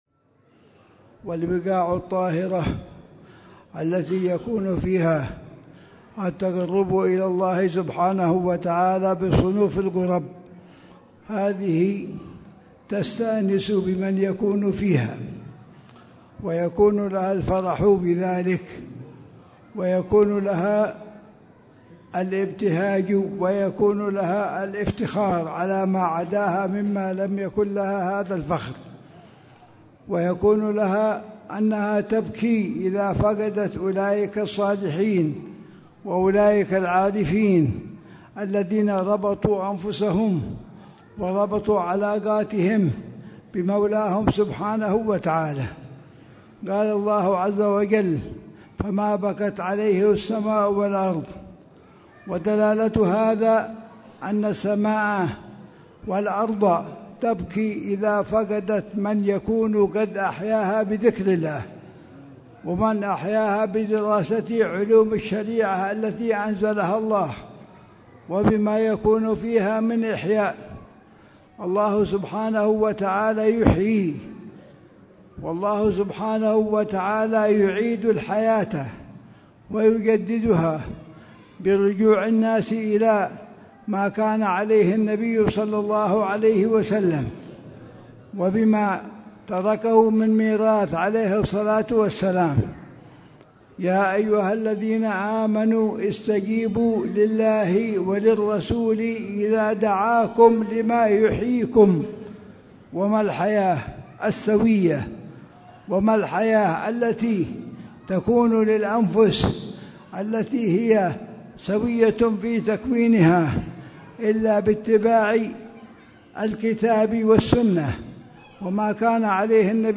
مذاكرة
في جامع منطقة الخريبة، في وادي دوعن، محافظة حضرموت